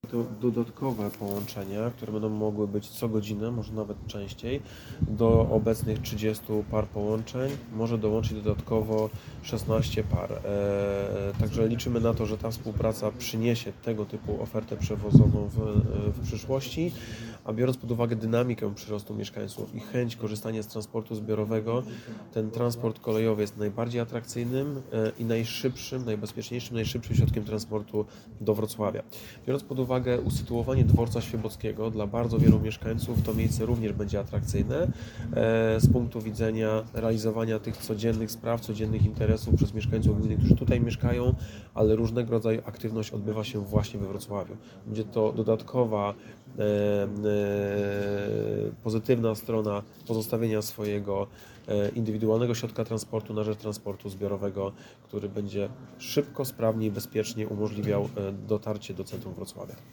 Na zorganizowanym dziś na dworcu kolejowym w Smolcu briefingu, burmistrz Miasta i Gminy Kąty Wrocławskie – Julian Żygadło mówił o rozwoju współpracy pomiędzy gminą a Kolejami Dolnośląskimi.